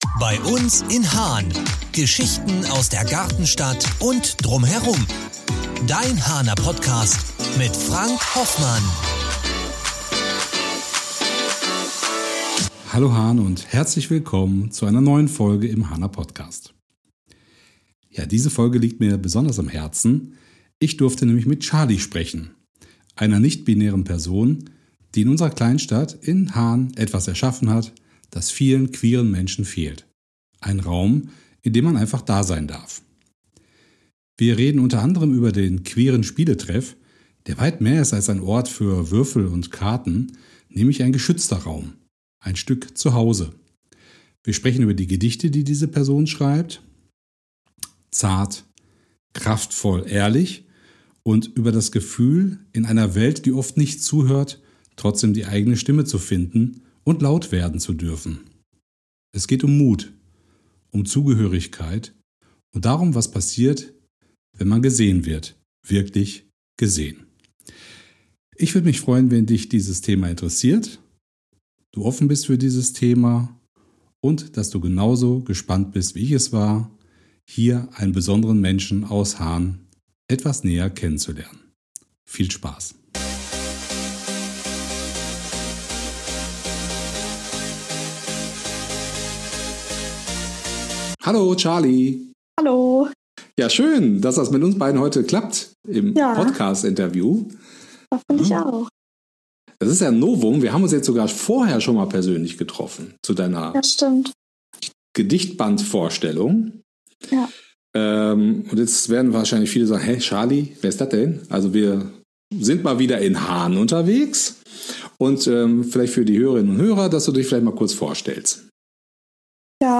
Ein Gespräch über Sichtbarkeit, Kreativität, Community und die Kraft, sich selbst zu erfinden.